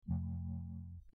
voice_mode_tone.mp3